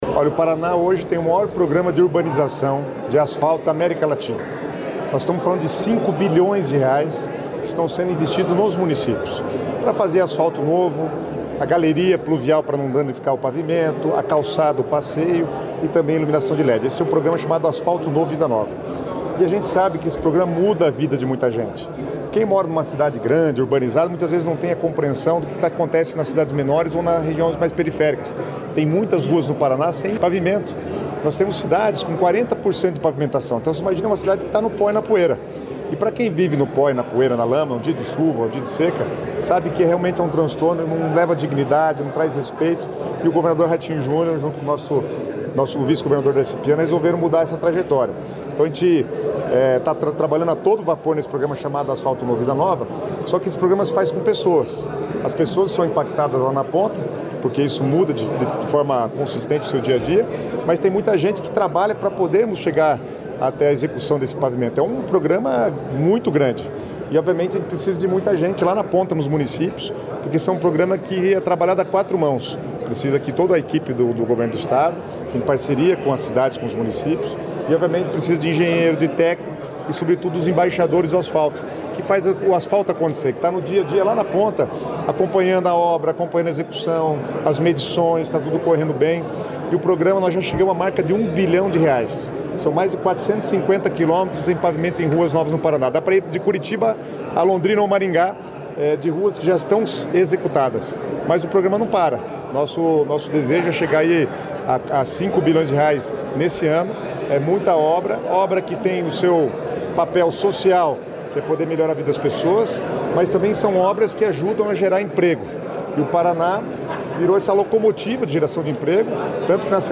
Sonora do secretário das Cidades, Guto Silva, sobre o projeto Embaixadores do Asfalto